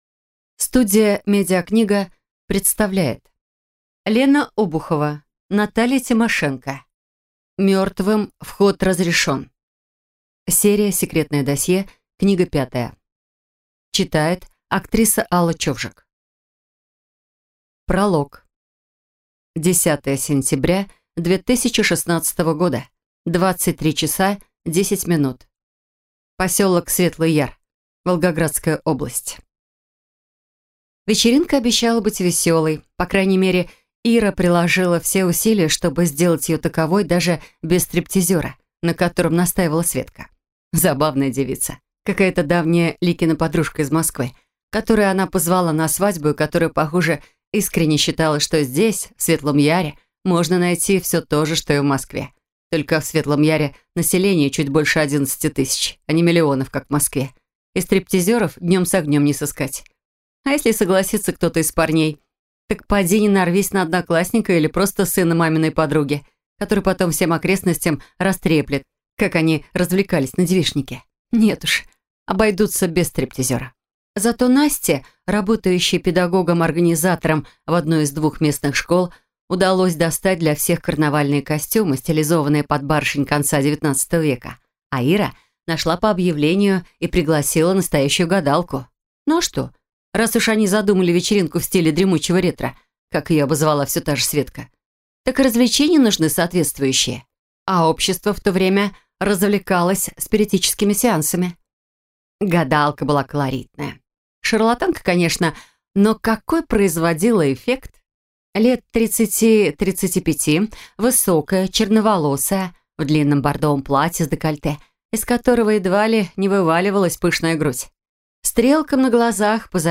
Аудиокнига «Мертвым вход разрешен» Наталья Обухова в интернет-магазине КнигоПоиск ✅ в аудиоформате ✅ Скачать Мертвым вход разрешен в mp3 или слушать онлайн